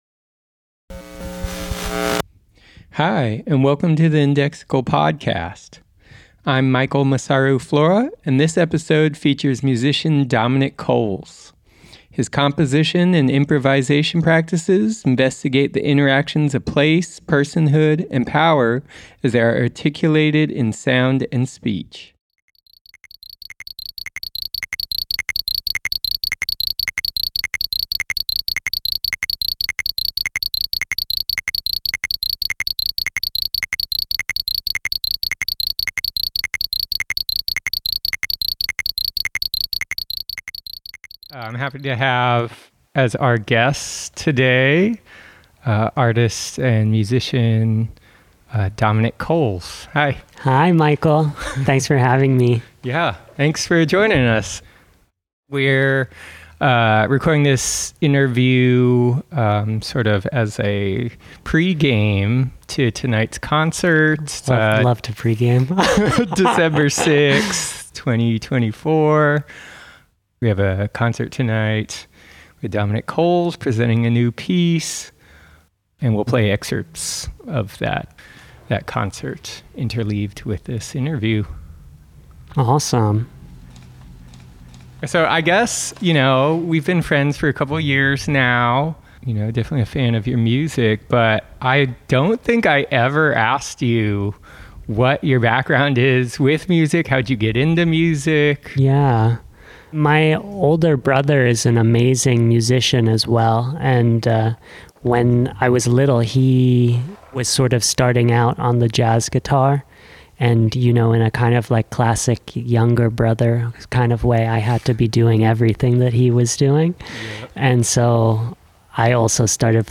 *** Note: There were a few audio glitches in this recording, but we felt the quality of the conversation was worth sharing.